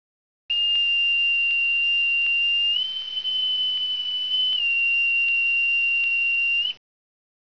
Bosun's Calls
PipedAboard.wav